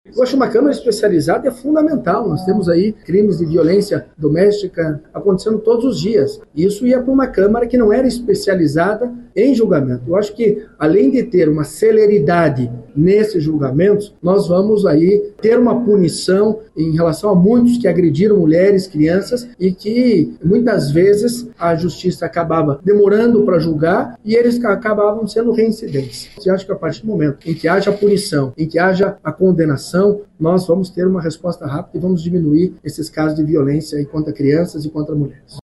Alexandre Curi (PSD), presidente da Assembleia Legislativa do Paraná, ressaltou que essa será a primeira Câmara Especializada em crimes de violência doméstica no país.